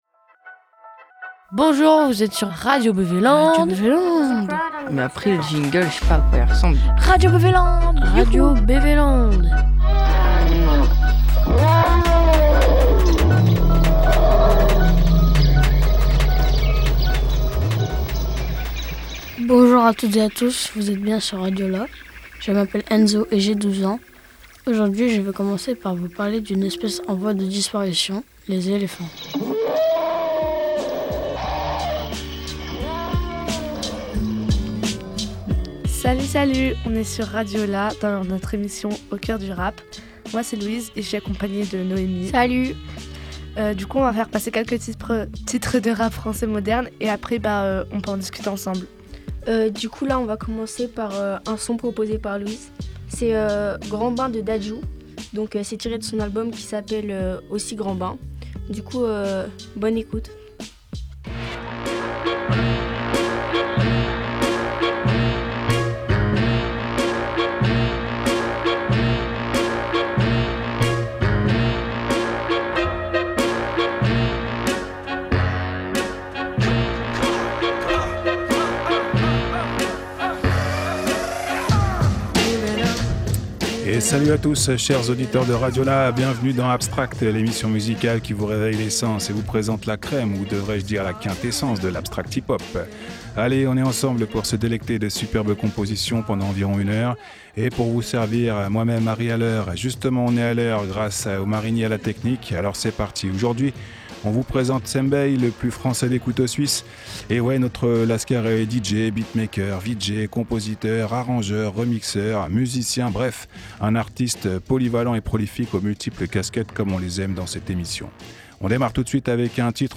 En 2024, RadioLà a semé ses micros ici ou ailleurs et récolté des voix plurielles, jeunes et moins jeunes, souvent originales et passionnées.
Du documentaire à la fiction, d’ateliers radio en émissions publiques, du pays de Dieulefit-Bourdeaux à la Mongolie ou l’Ouganda… Le tout en musique.
Radio Bizz’Art – en direct du festival Oasis Bizz’Art de Dieulefit
Micro-trottoir